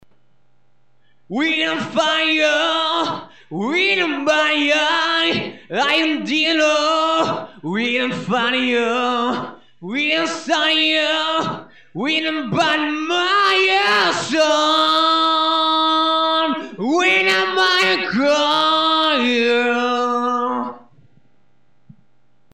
Ca faisait un p'tit moment, et là je viens d'avoir un cable pour m'enregistrer sur mon pc donc j'en ai profiter pour m'amuser, j'ai enregistrer 2/3 trucs à l'arrache avec juste la voix, sans parole, c'est du "yaourt" donc , 2 p'tite compos et un trip (enfin le tout est un trip aussi
(çà fait zarb sans musique derrière,lol)
moi je trouve ça très naturel, ya de l'assurance dans la voix,